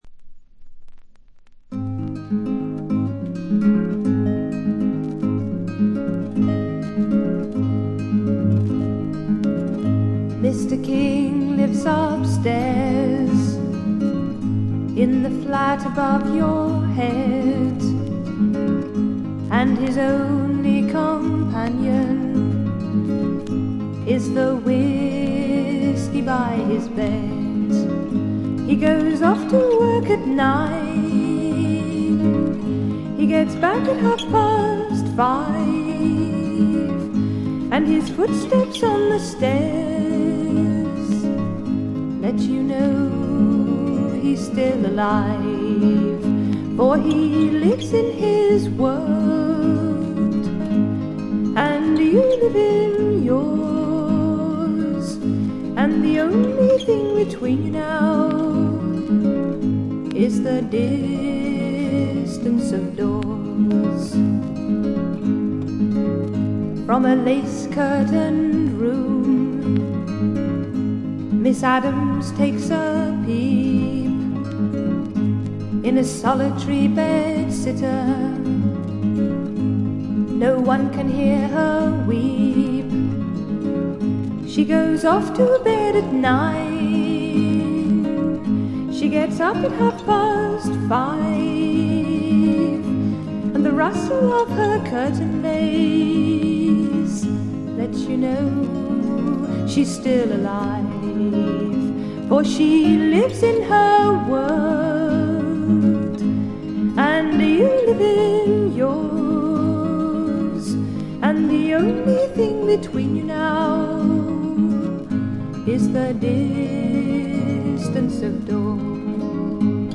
静音部でバックグラウンドノイズ、ところどころでチリプチ、散発的なプツ音少し。
素朴、可憐、清楚といったキーワードがぴったりはまる英国の女性フォーキーらしさ満開の名作ですね。
試聴曲は現品からの取り込み音源です。